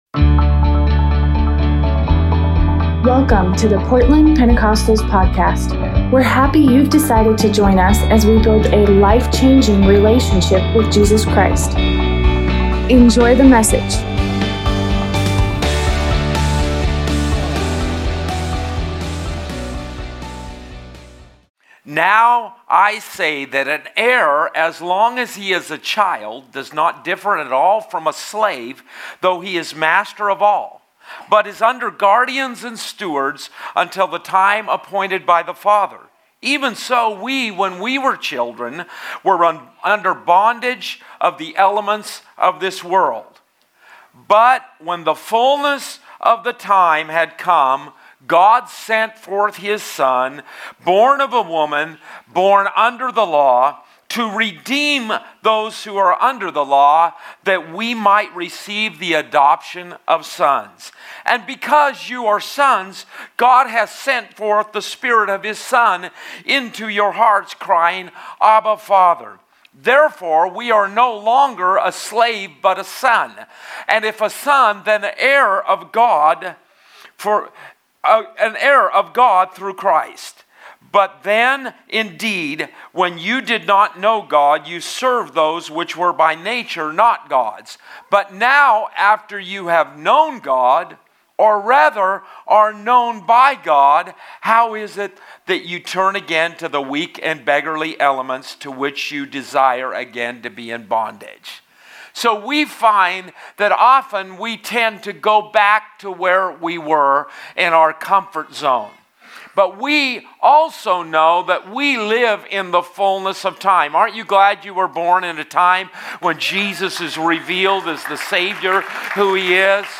Christmas Sunday sermon